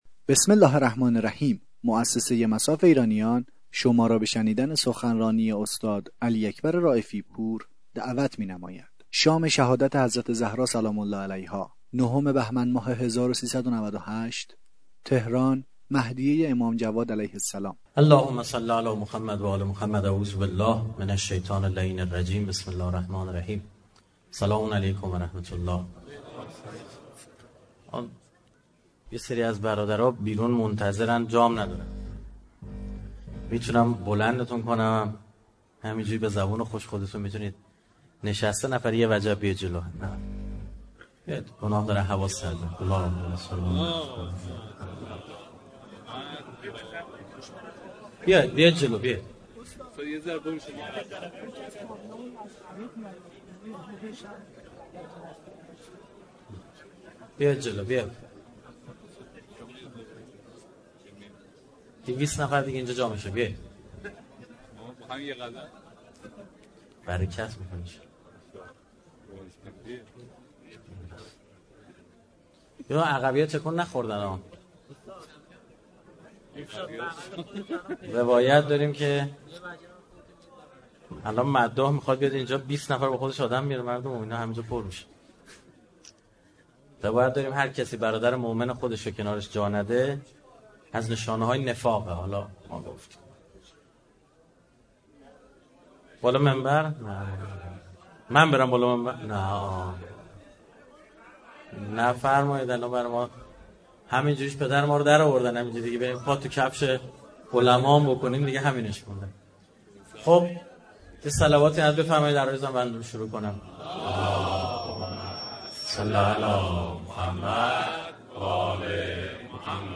سخنرانی استاد رائفی‌پور در شام شهادت حضرت زهرا (س)